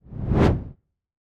RezWooshOnly.wav